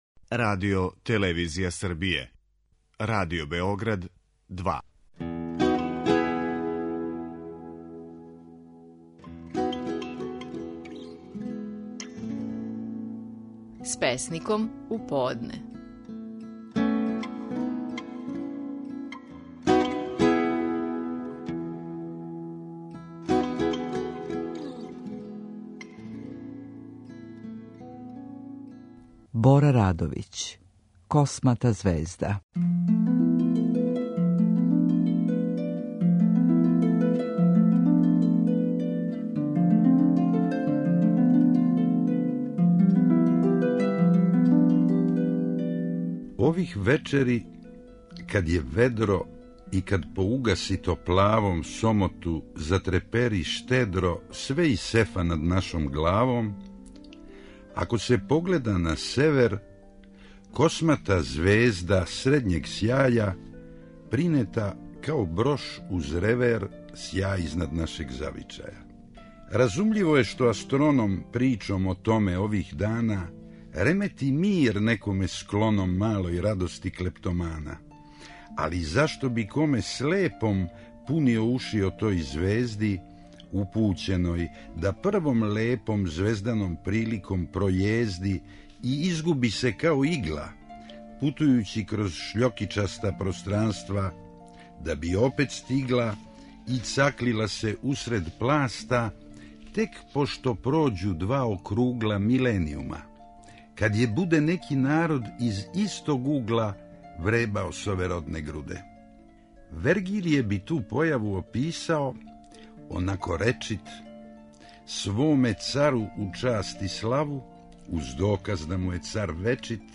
Стихови наших најпознатијих песника, у интерпретацији аутора
Бора Радовић говори своју песму: "Космата звезда".